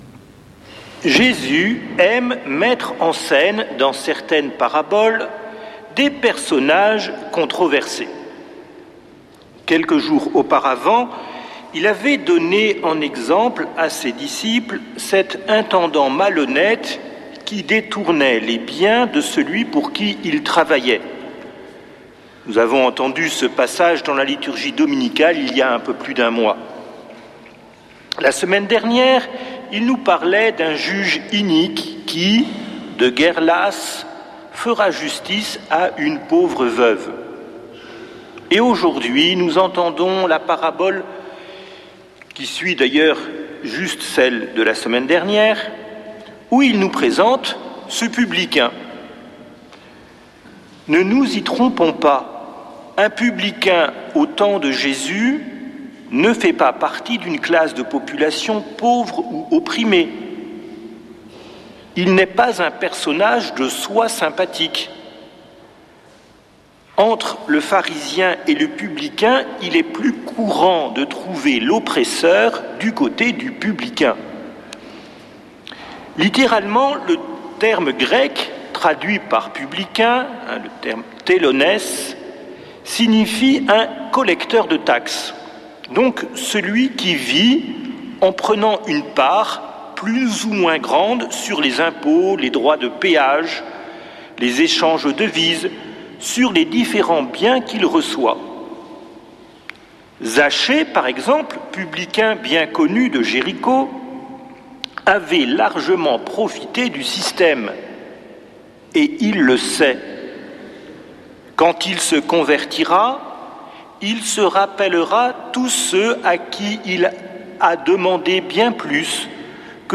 dimanche 26 octobre 2025 Messe depuis le couvent des Dominicains de Toulouse Durée 01 h 28 min